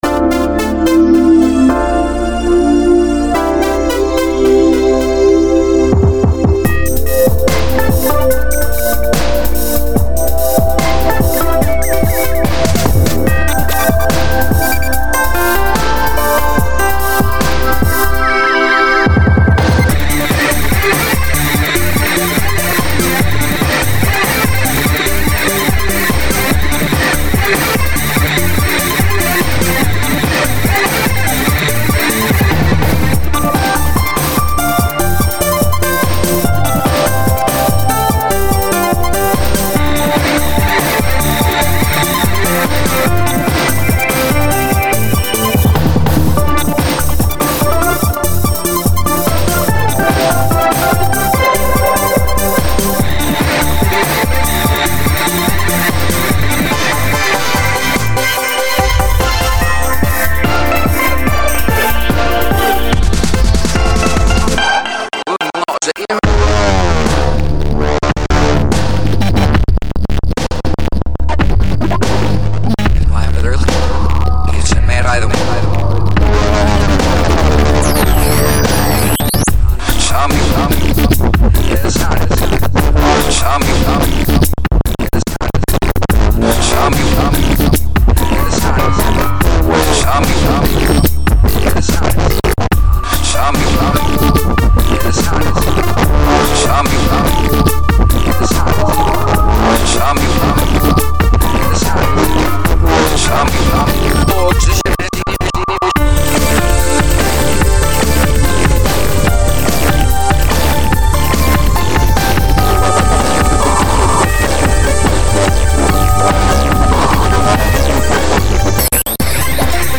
Original mp3 music
Backward voices and stuff.